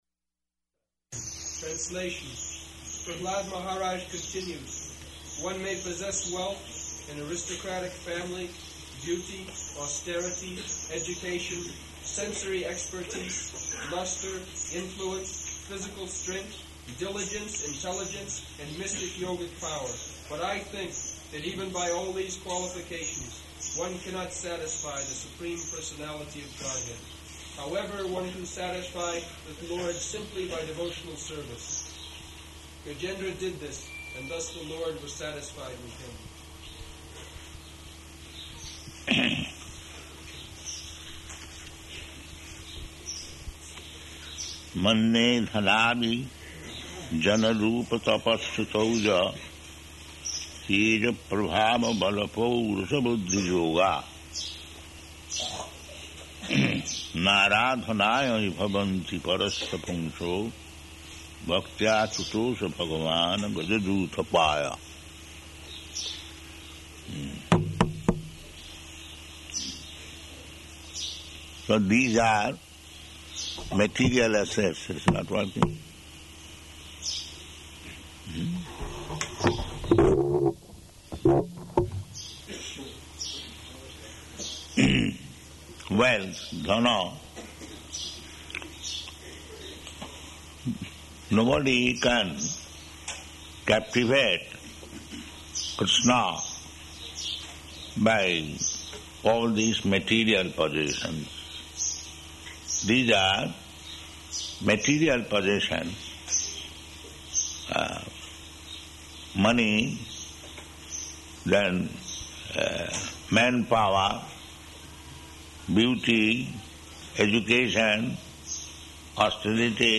Type: Srimad-Bhagavatam
Location: Māyāpur